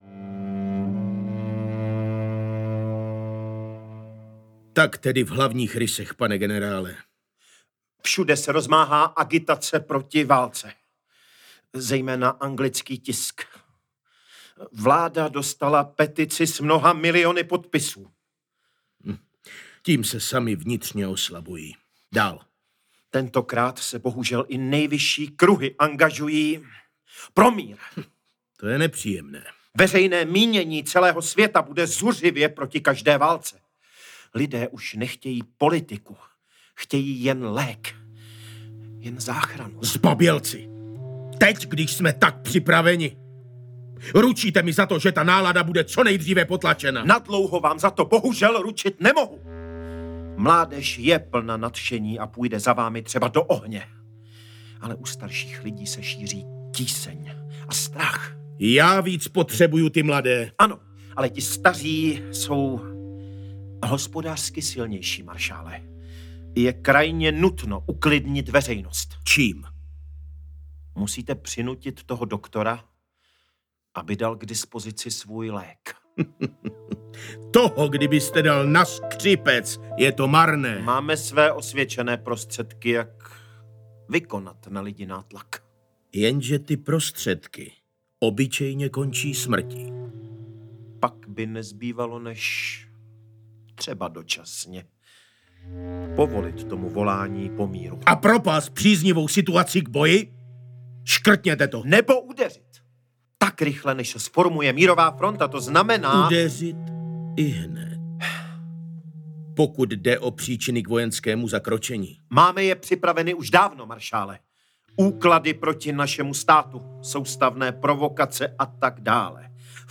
Bílá nemoc audiokniha
Ukázka z knihy